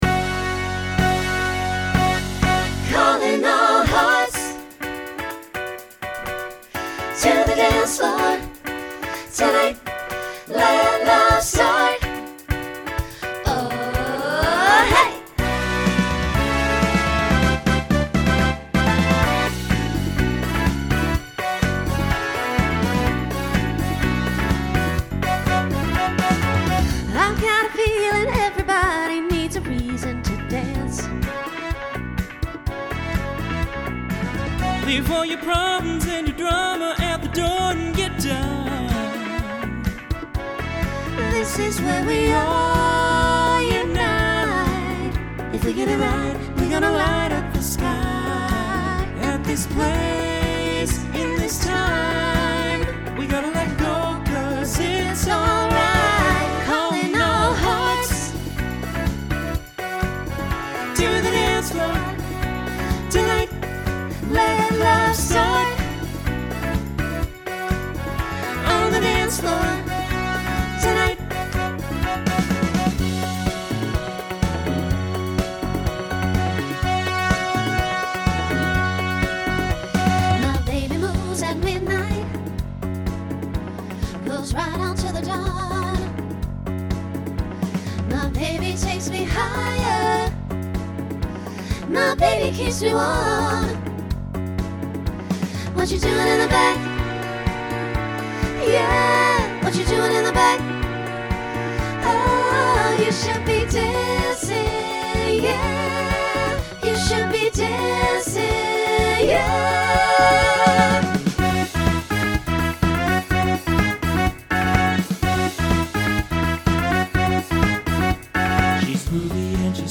Genre Disco , Pop/Dance Instrumental combo
Transition Voicing Mixed